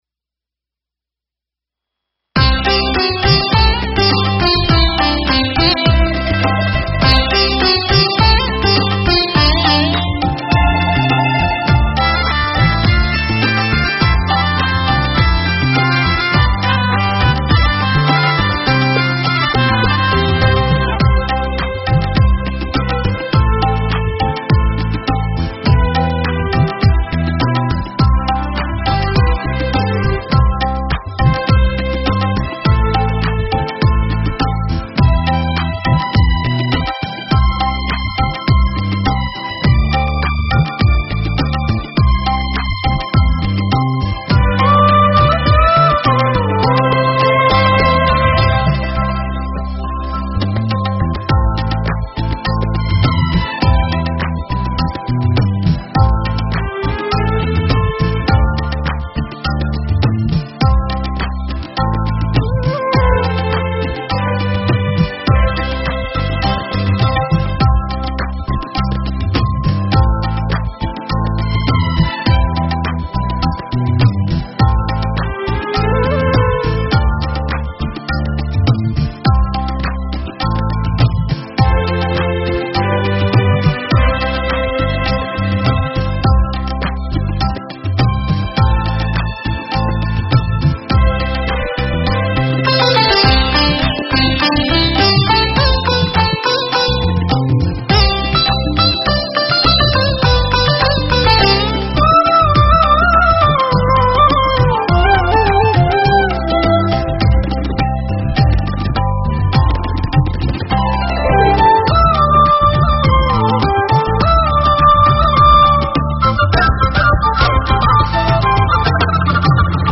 College Anthem Instrumental Download: Click Here
Puharar_Sandhan_Minus_Track.mp3